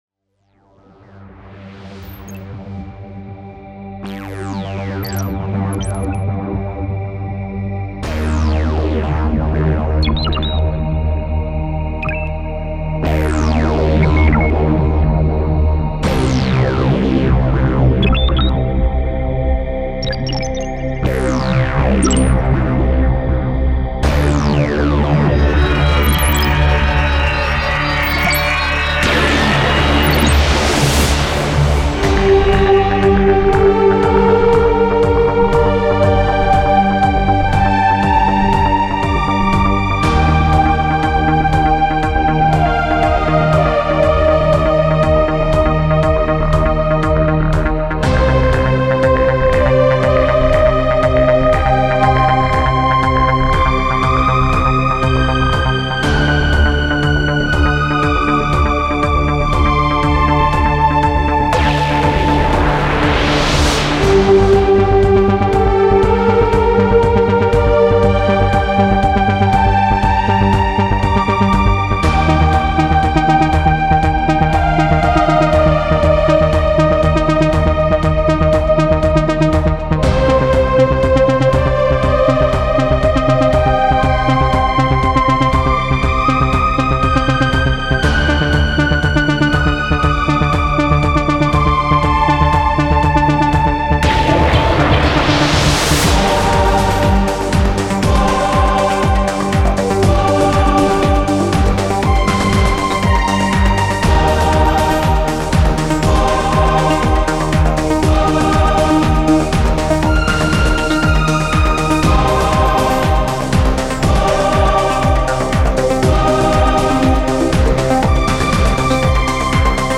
Композиция в стиле New Age